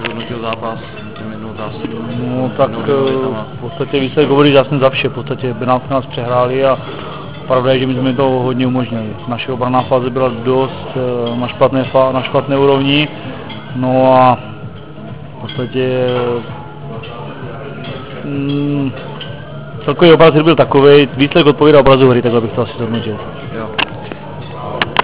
Zvukový záznam rozhovoru